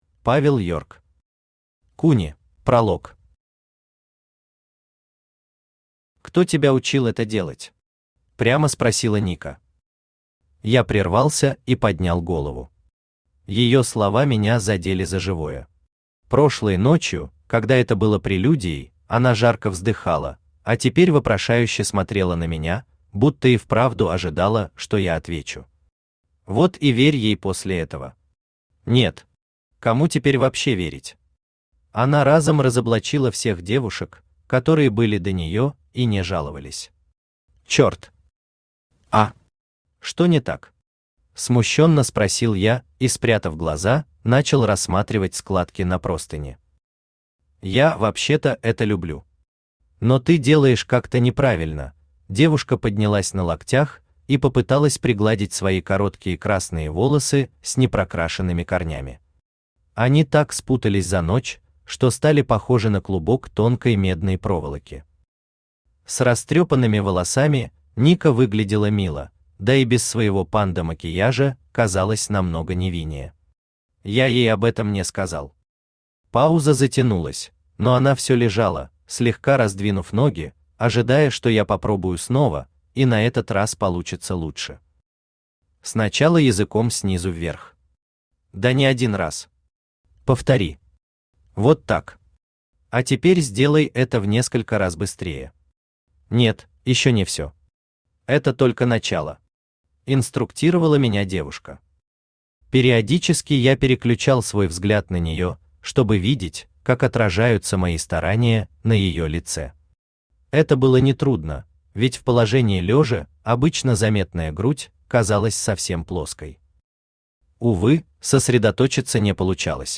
Аудиокнига Куни | Библиотека аудиокниг
Aудиокнига Куни Автор Павел Йорк Читает аудиокнигу Авточтец ЛитРес.